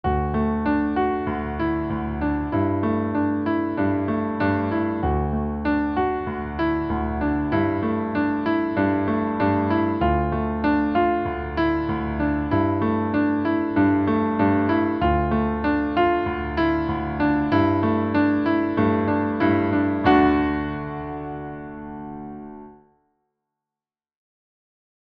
I play the two-chord progression twice in the major key (as on the record), and then twice changing the first chord into a minor instead of a major.
The minor version definitely sounds dirgey.
brickminor.mp3